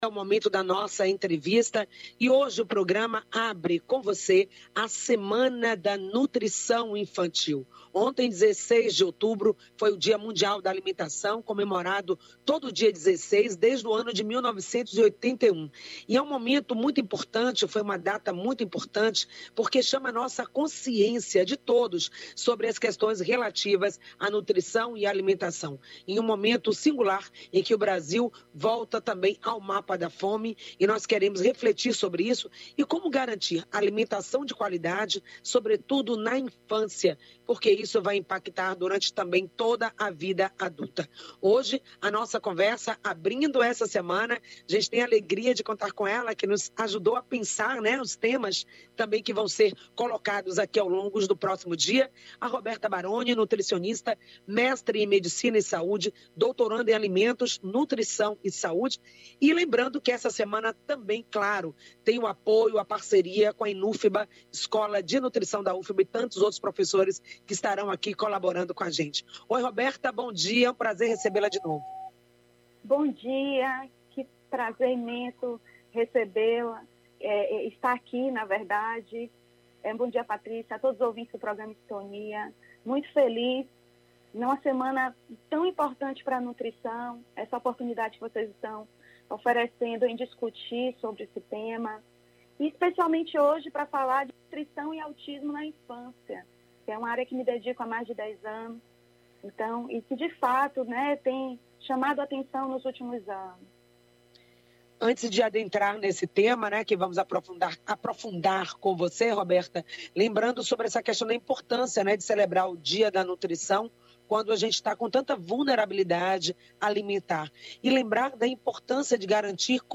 Entre outras questões o programa tratou sobre o desenvolvimento neurológico, alterações sensoriais comuns no autismo, dificuldade alimentar e seletividade alimentar,e cuidados da Nutrição. Ouça a entrevista na íntegra: